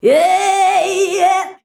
YEAEAYEAH.wav